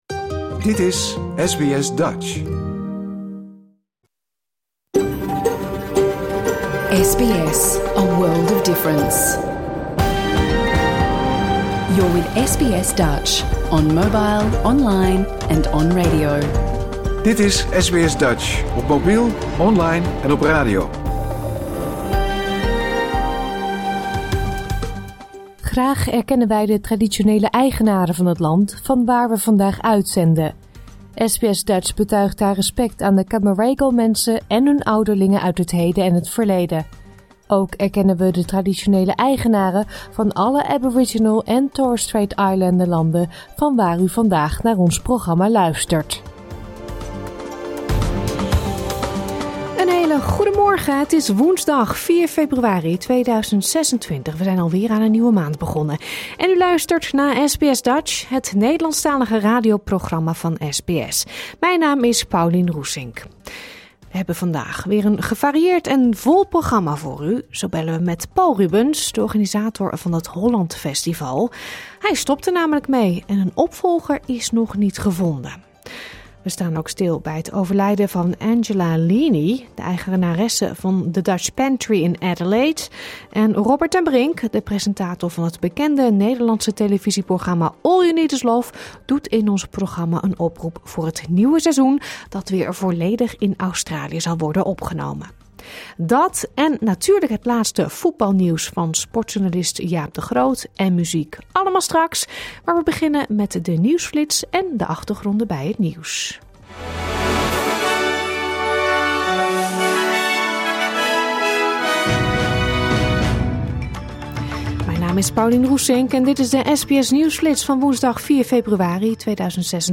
Luister hier de uitzending van woensdag 4 februari 2026 (bijna) integraal terug.